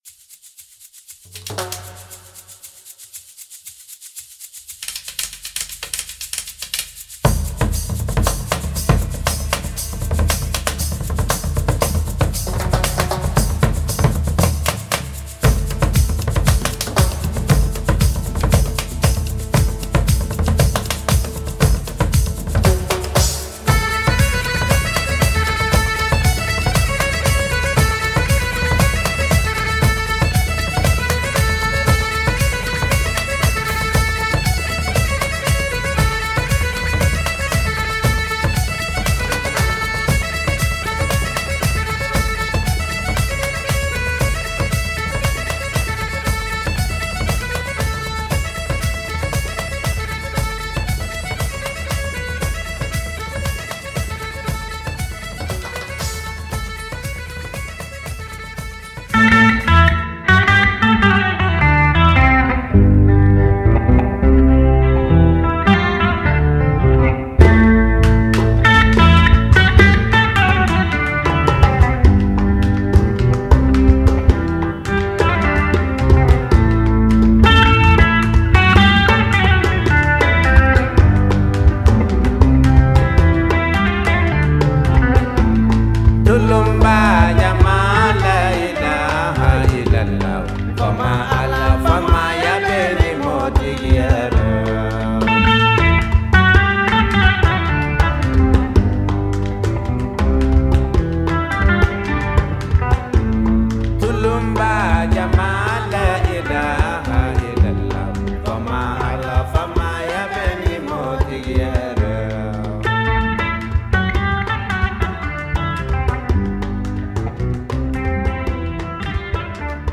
the Scots stomp
accordion work in the Cajun tradition